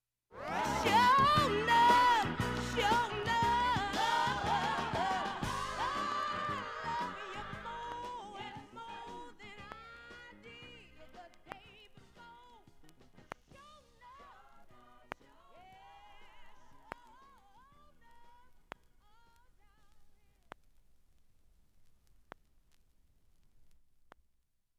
音質良好全曲試聴済み。
５回までのかすかなプツが１箇所
３回までのかすかなプツが２箇所